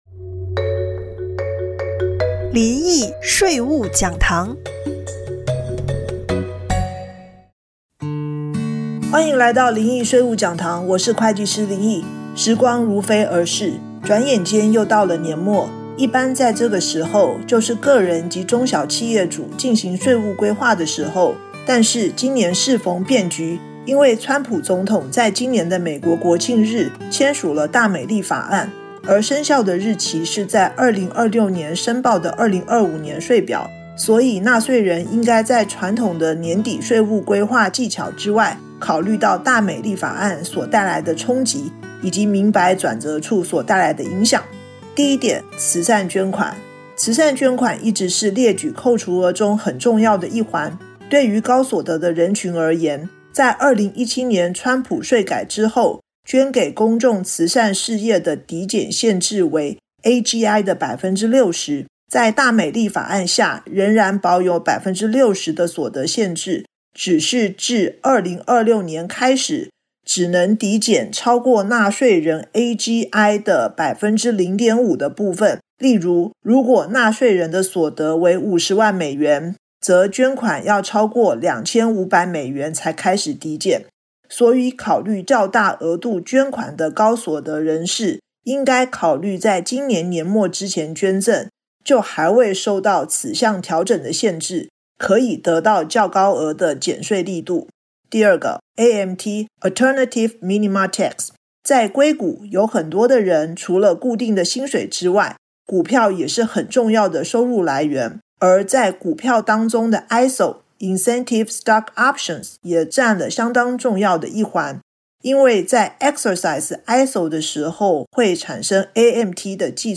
電台訪談